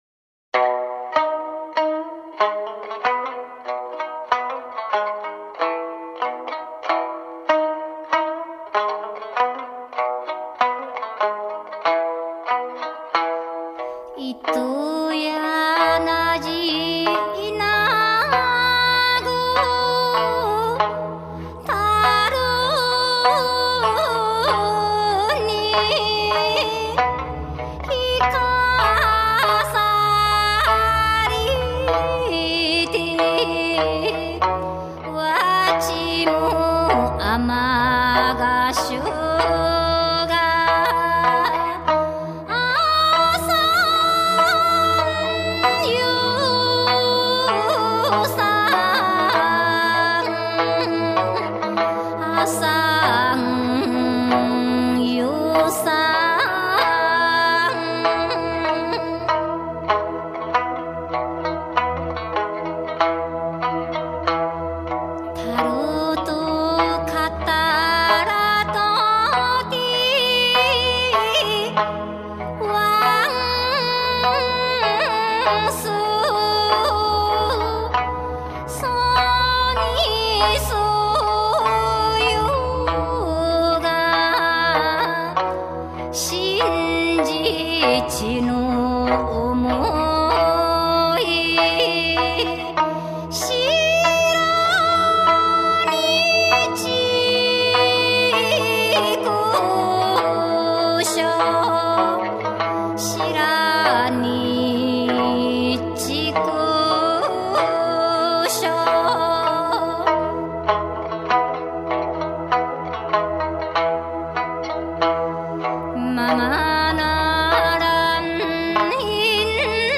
飘渺的人声混音仿佛把人们带入梦一般的冲绳民谣世界。